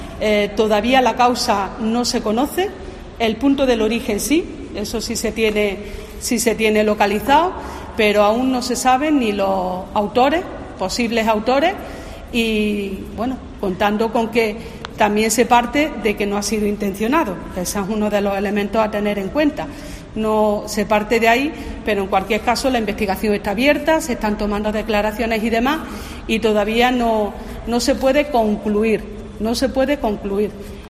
Sandra García, delegada del Gobierno en Andalucía
En declaraciones a los periodistas en San Fernando (Cádiz), García ha señalado que el Seprona de la Guardia Civil está investigando las causas con el apoyo de otro grupo a nivel nacional de la Oficina de Madrid.